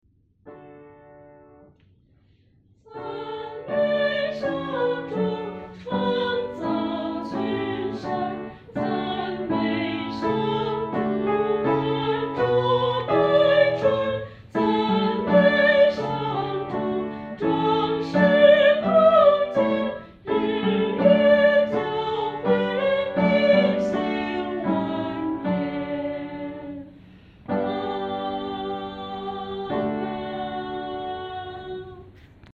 女高
这首诗的曲调活跃，和声变化色彩丰富；与前面五首赞美诗的和声处理方面很不同，是一首典型的“众赞歌”。